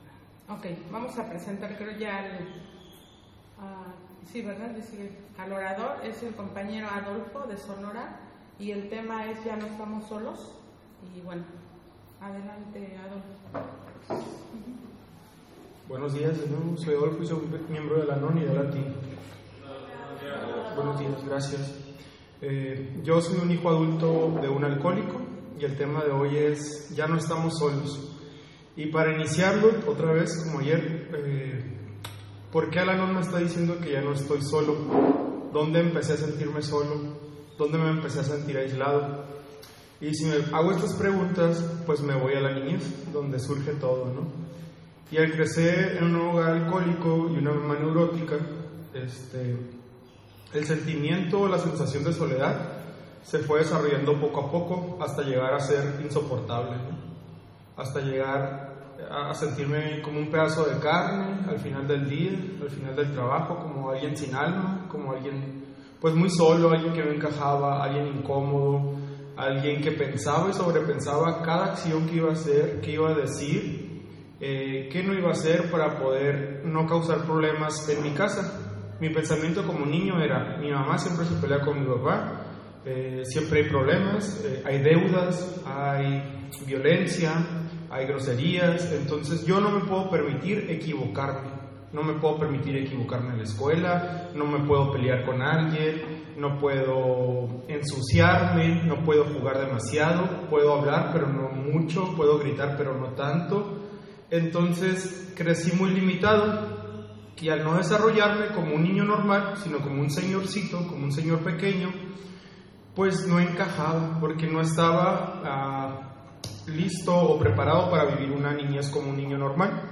Obregón, Mexico - 2024 Serenity By The Sea - Ventura CA